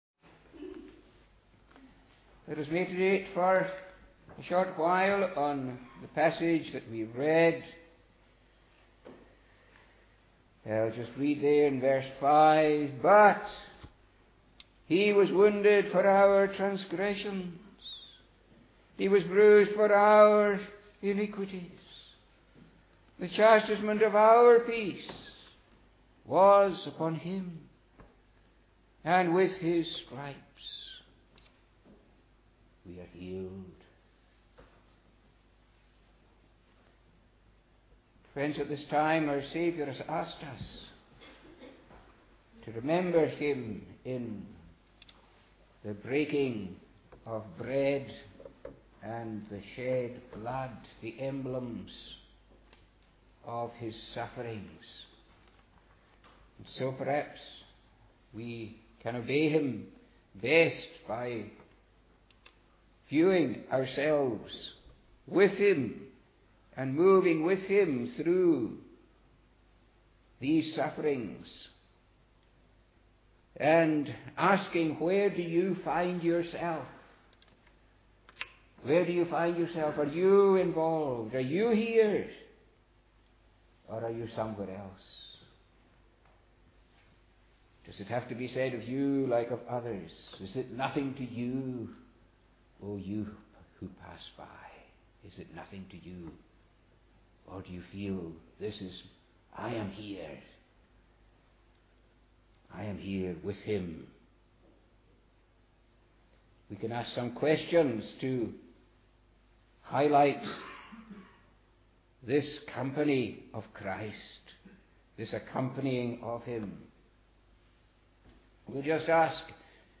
The following sermon
on the Sabbath Morning Communion Service, 3rd April, 2011.